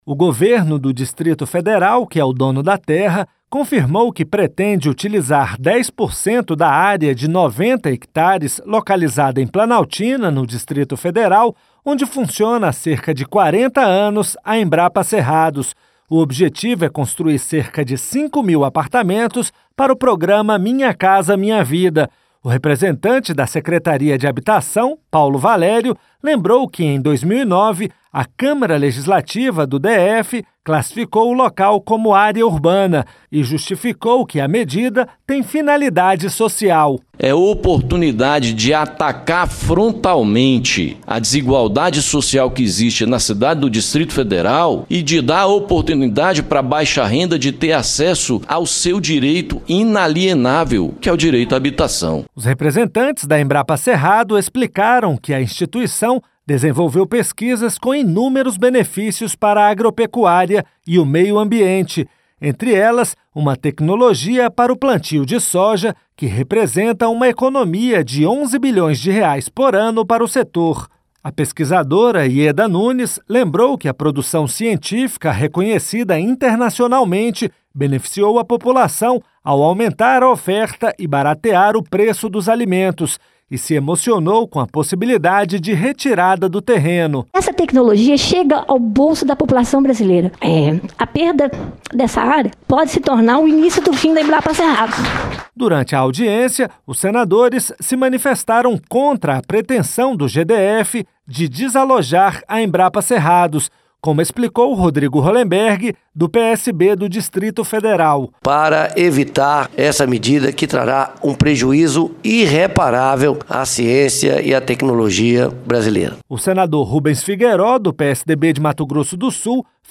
RadioAgência